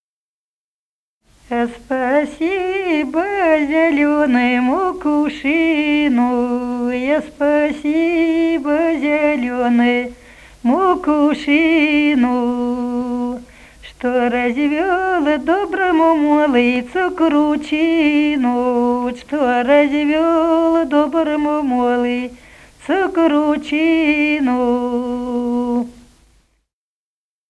А спасибо зелёному кувшину (баллада) И0029-12.mp3 — Музыкально—фольклорный депозитарий
Народные песни Смоленской области